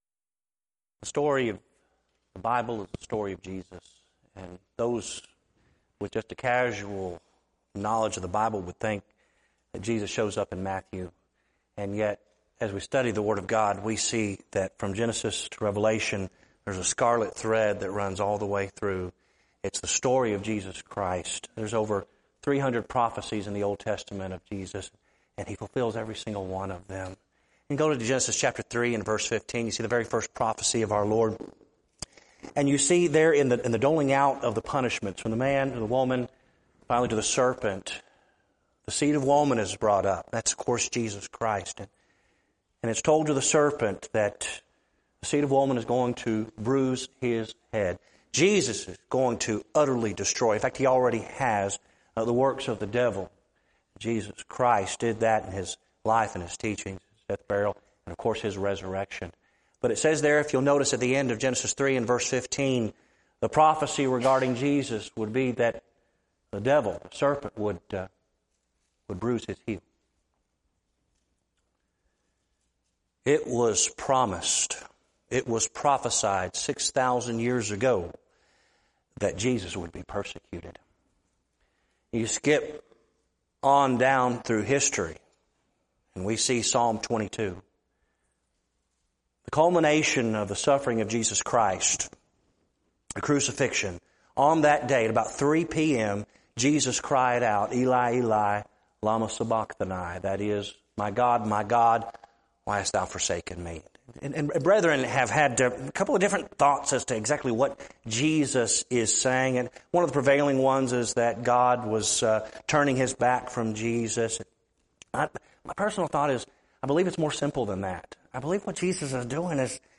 Event: 8th Annual BCS Men's Development Conference
lecture